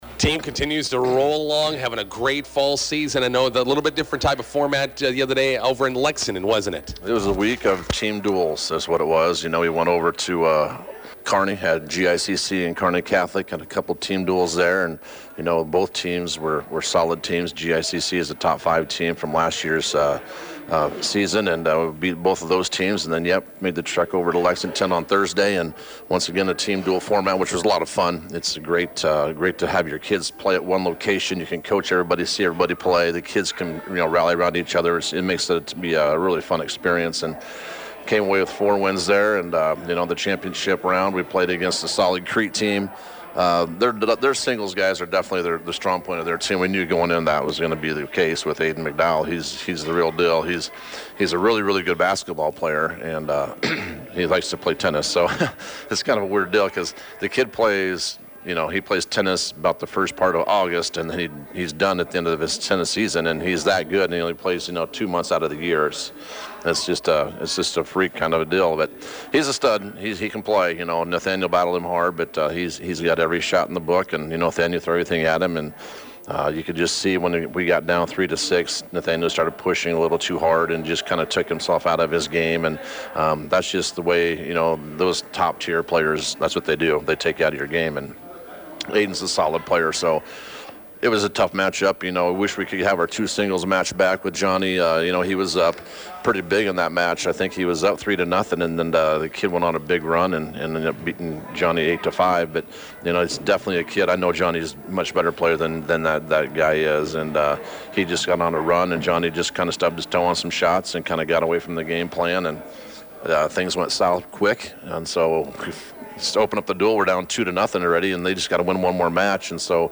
INTERVIEW: Bison boys tennis brace for busy week, starting tonight in North Platte.